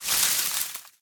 328d67128d Divergent / mods / Soundscape Overhaul / gamedata / sounds / material / human / step / bush02gr.ogg 13 KiB (Stored with Git LFS) Raw History
bush02gr.ogg